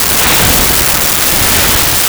Flyby3
flyby3.wav